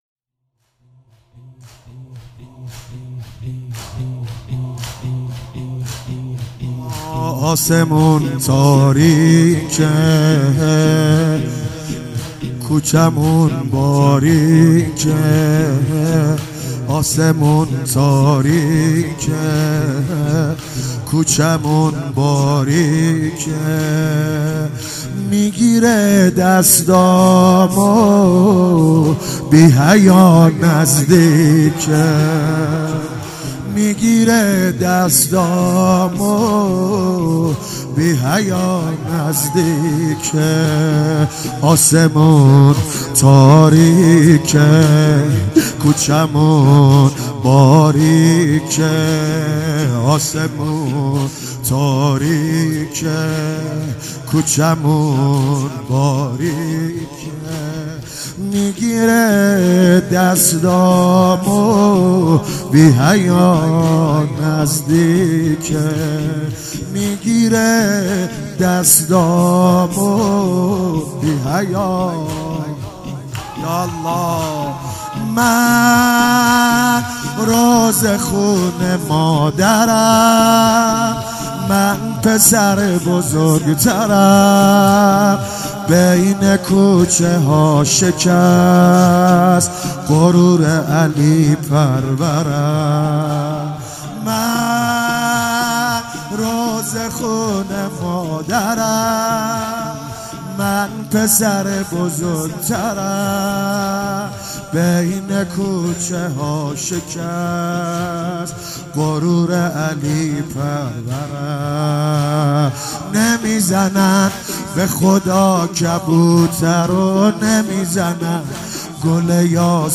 زمینه مداحی